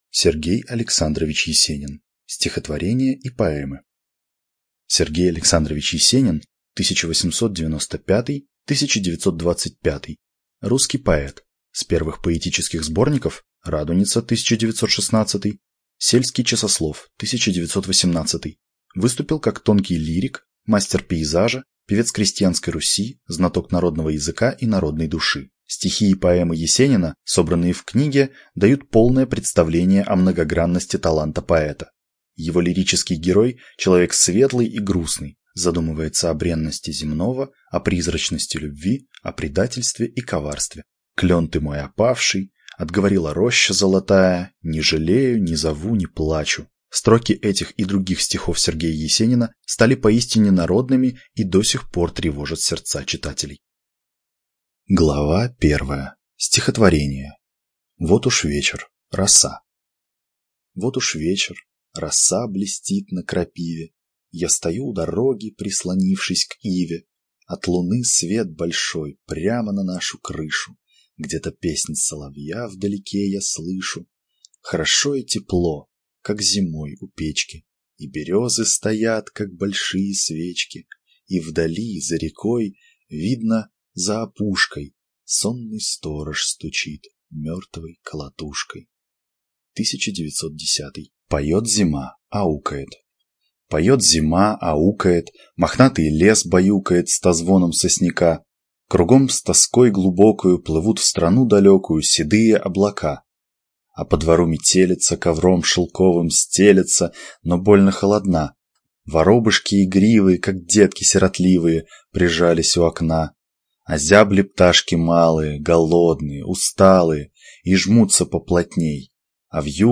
ЖанрПоэзия